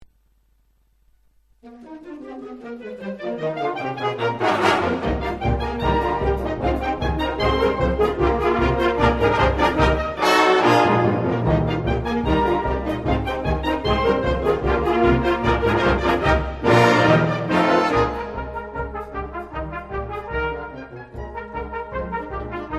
ORCHESTRE D'HARMONIE DE BOUZONVILLE Direction